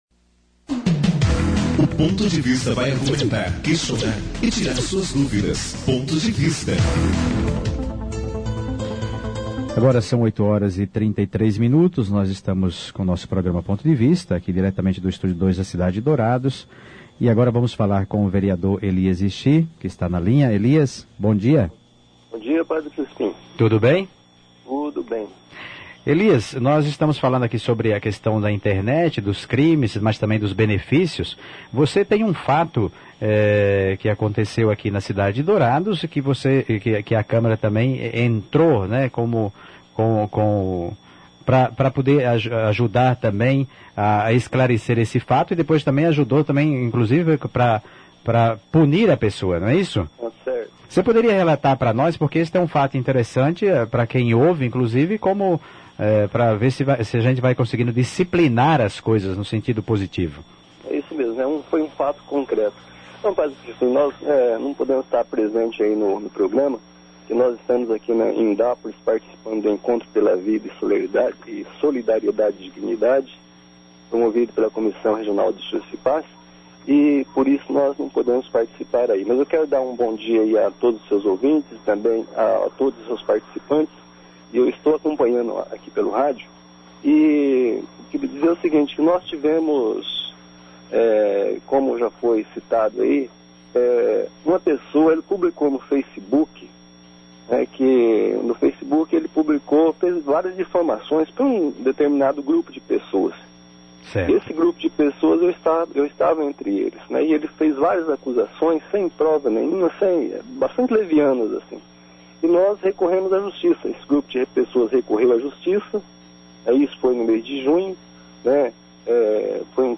Ponto de Vista discute os crimes cibernéticos - Rádio Coração - Rádio Católica FM em Dourados e Região - MS!